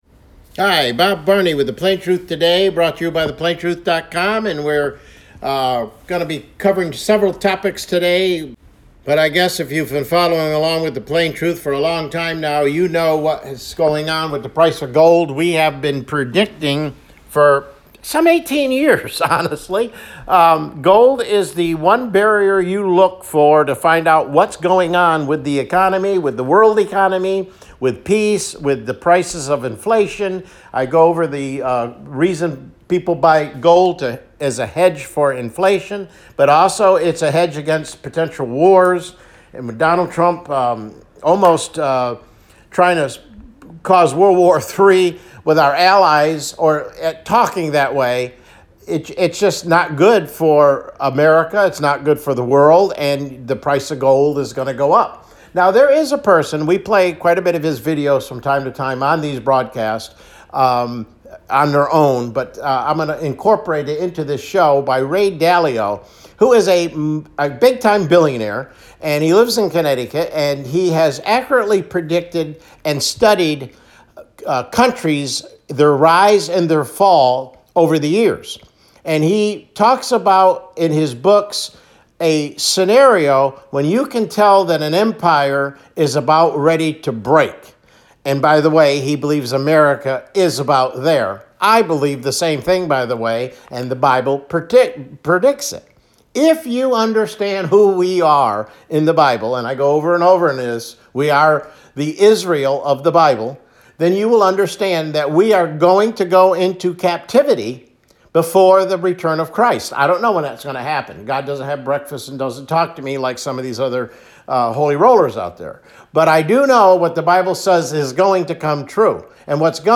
CLICK HERE TO LISTEN TO THE PLAIN TRUTH TODAY MIDDAY BROADCAST